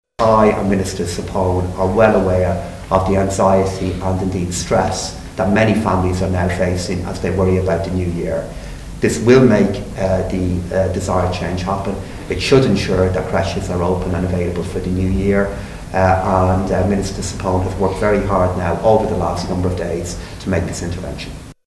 But Finance Minister Pascal Donohoe believes the new funding will help: